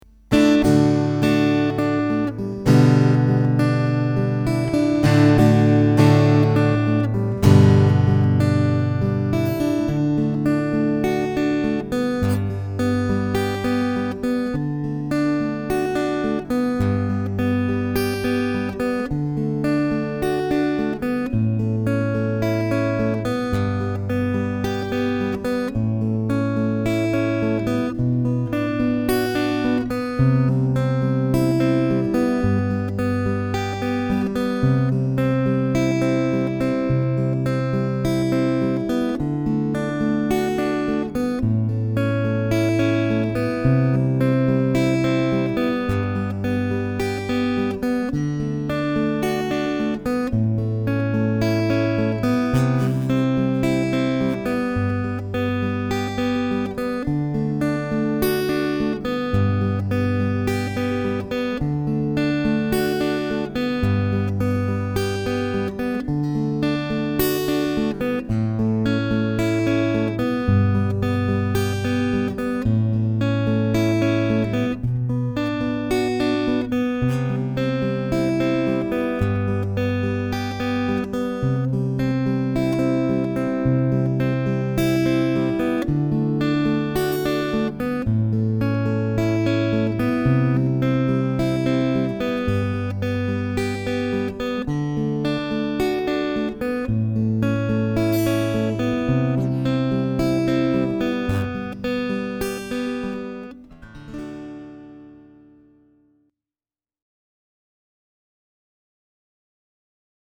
Une petite reprise